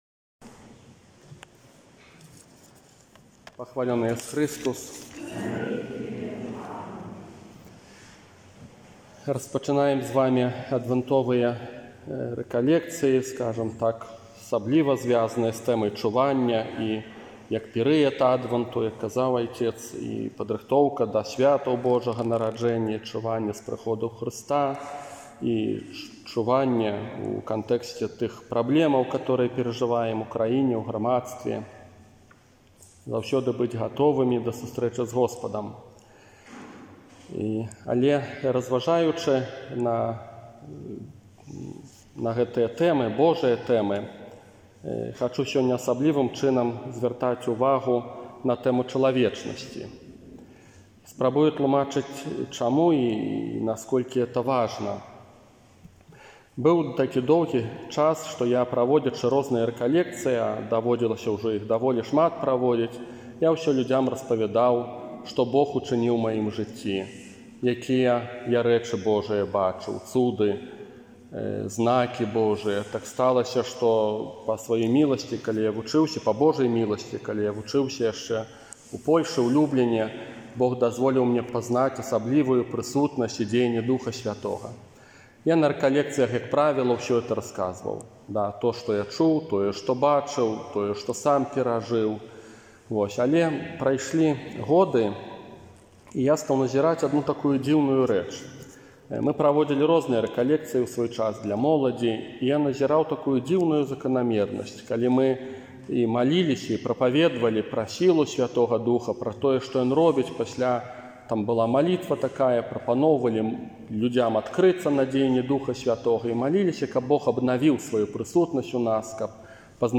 Для тых, хто не меў магчымасці ўдзельнічаць у Адвэнтавых рэкалекцыях, а таксама для ўсіх зацікаўленых, прапаную рэкалекцыі, якія адбыліся ў Вялікай Бераставіцы